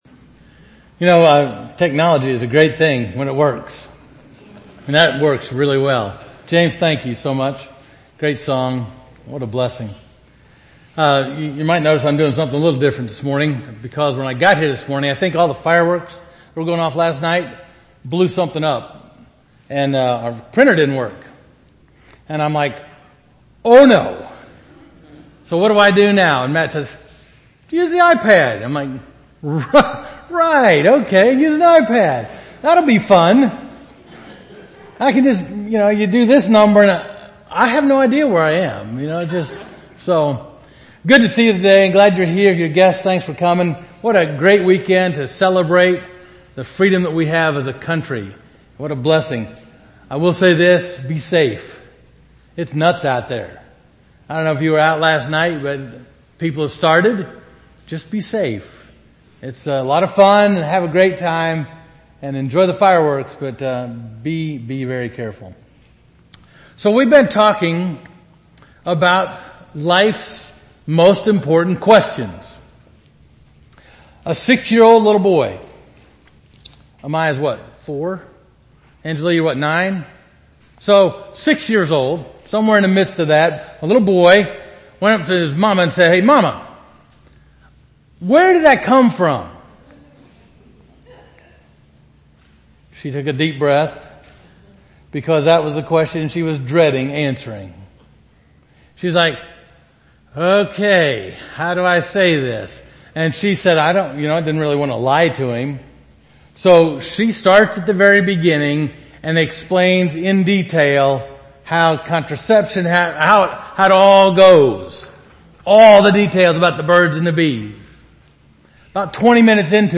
Covenant United Methodist Church Sermons